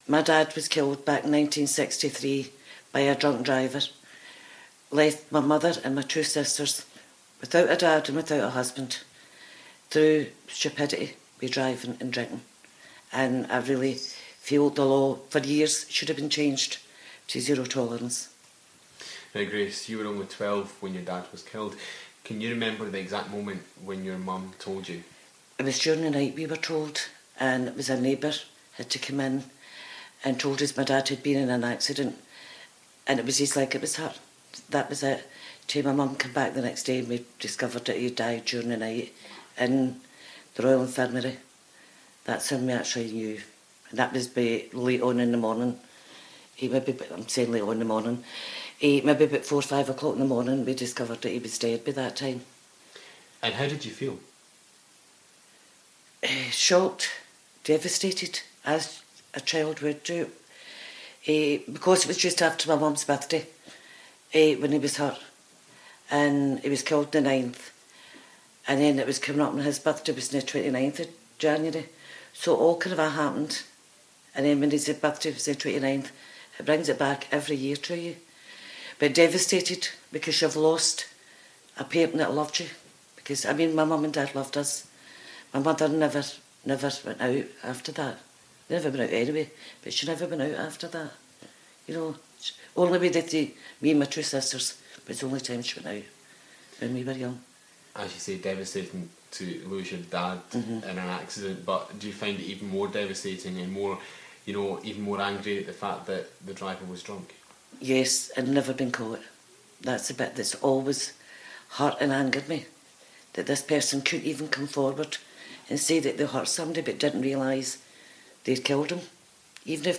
has been speaking to a woman